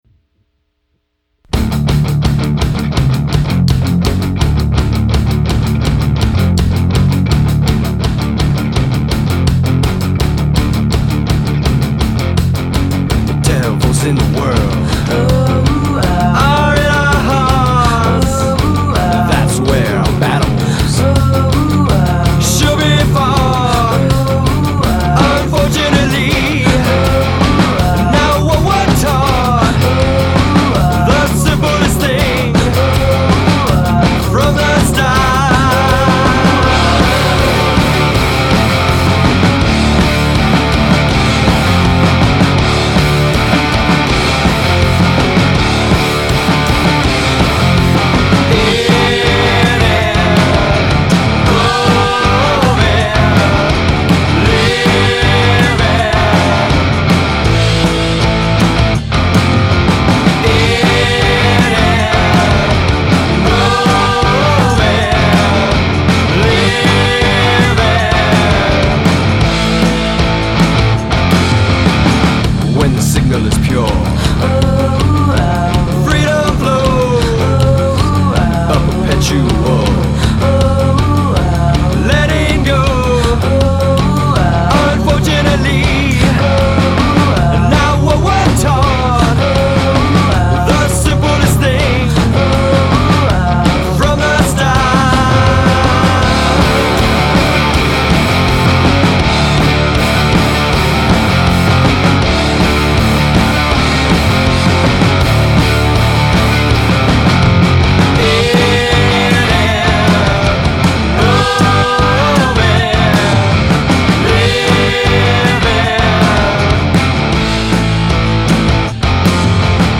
totally groovy, funky vibe
vocals, keys, guitar, bass, RC-50,  percussion
drums